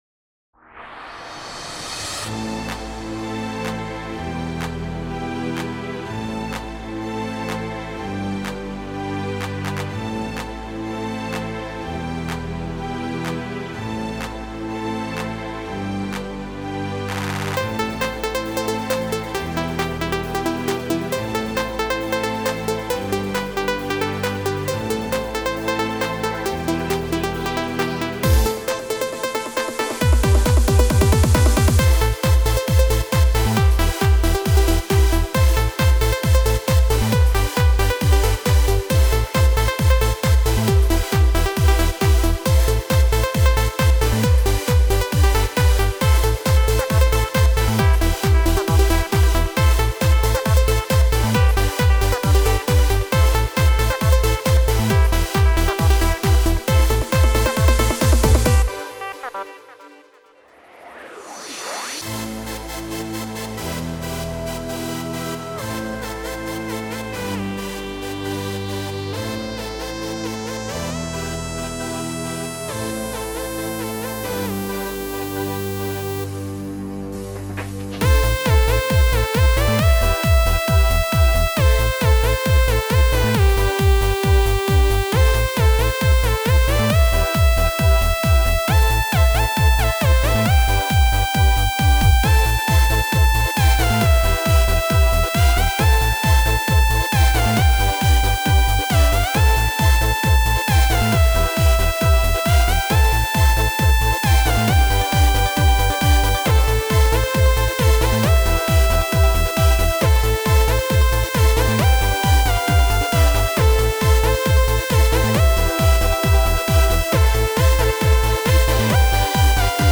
Kellemes, utazós zene lett belőle.